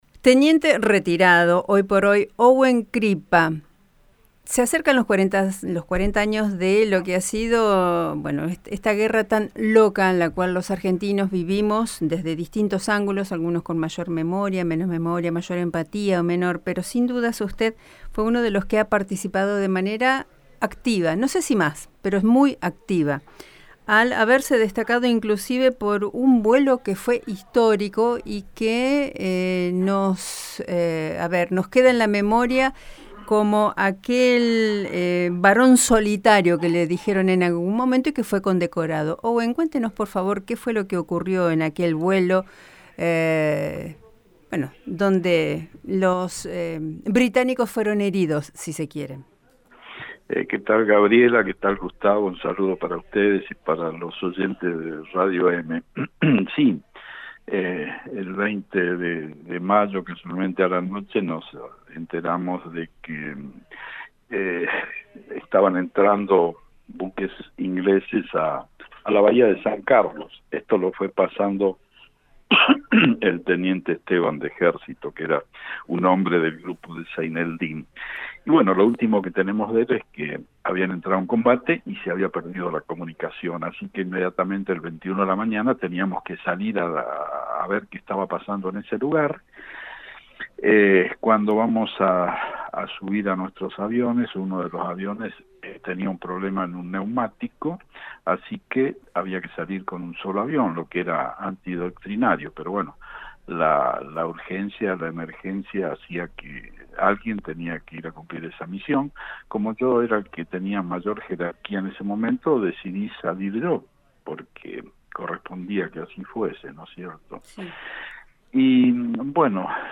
En una fecha histórica, radio EME invitó a Veteranos y Veteranas de Guerra a compartir sus pensamientos y vivencias.